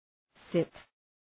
Προφορά
{sıt}
sit.mp3